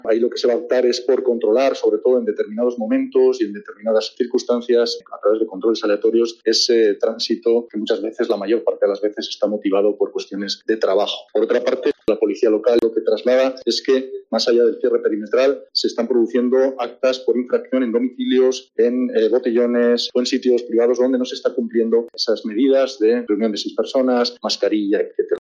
Pablo Hermoso de Mendoza, alcalde de Logroño